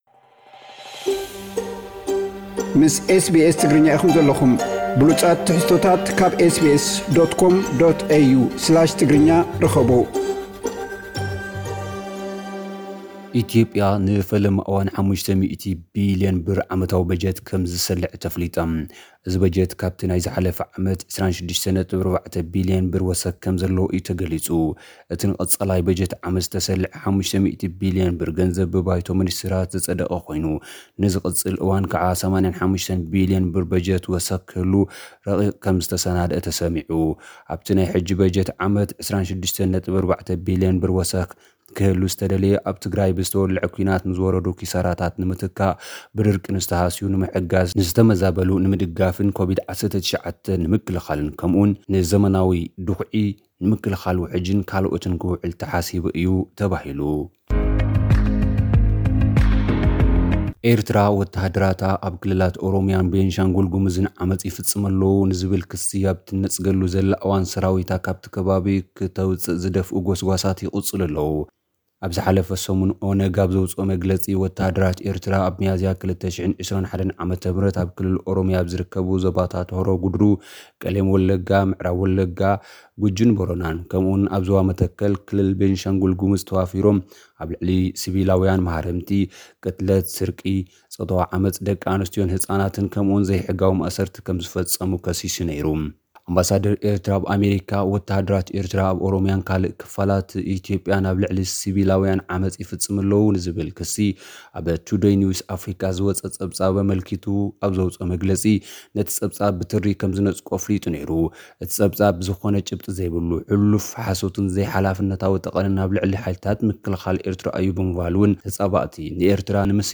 ሓጸርቲ ጸብጻባት፥ ኤርትራ ወተሃደራታ ኣብ ክልላት ኦሮሚያን ቤንሻንጉል ጉምዝን ተዋፊሮም ኣብ ልዕሊ ሲቪላውያን ዓመጽ ይፍጽሙ ኣለው ንዝብል ክሲ ኣብ ትጽገሉ እዋን ፤ ሰራዊት ኤርትራ ካብቶም ቦታታት ይውጽኡ ዝብል ጎስጓስ ተወለድቲ ኦሮም ተጀሚሩ። ባይቶ ሚኒስትራት ኢትዮጵያ 26.4 ቢልዮን ብር ወሰኽ ዘለዎ ሓዱሽ በጀት ኣጽዲቑ። ዝብሉ ሓጸርቲ ጸብጻባት ልኡኽና ክቐርብዩ።